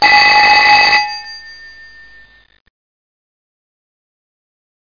tel_laut.mp3